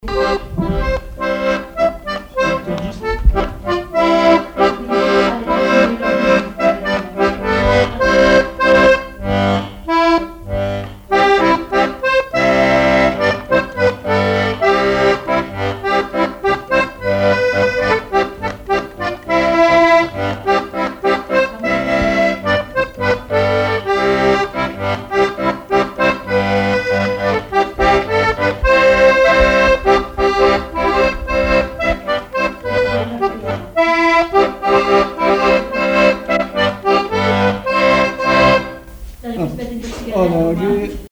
Sainte-Pexine
danse : quadrille : avant-deux
accordéon chromatique
Pièce musicale inédite